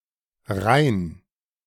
Rain (German pronunciation: [ʁaɪn]
De-Rain.ogg.mp3